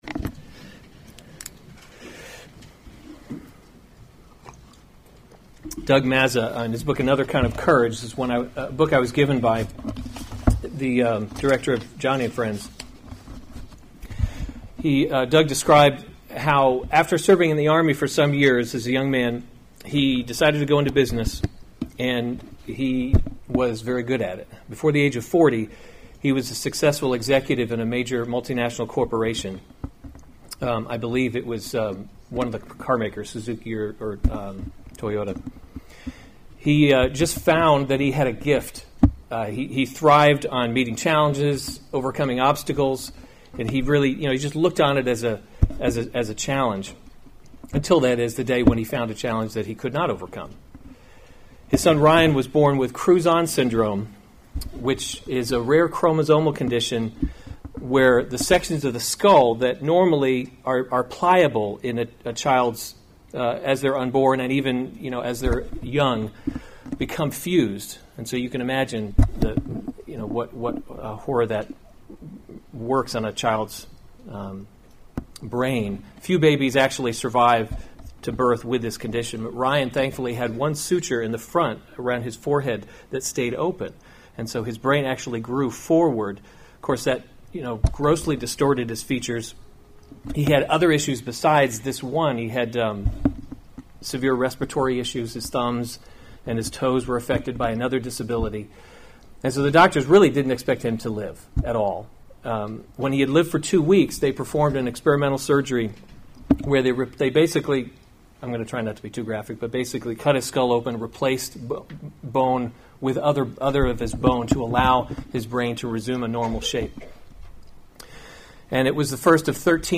August 4, 2018 Psalms – Summer Series series Weekly Sunday Service Save/Download this sermon Psalm 79 Other sermons from Psalm How Long, O Lord?